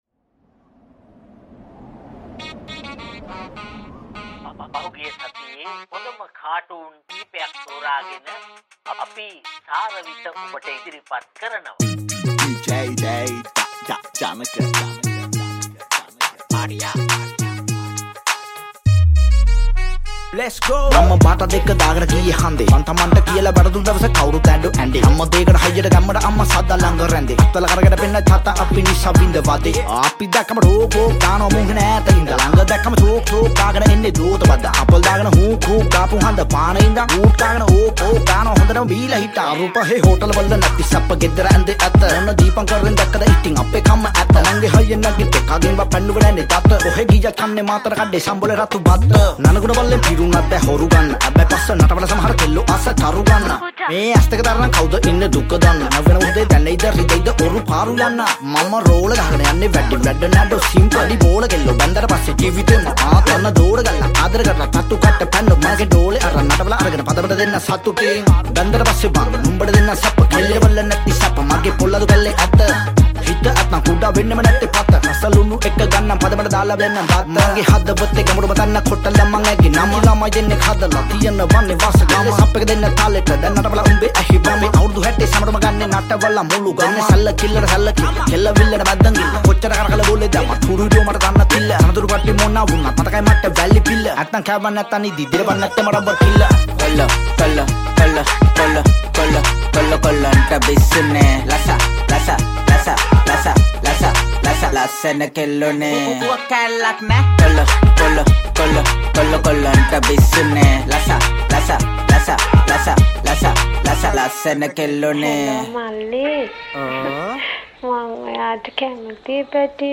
remix
Rap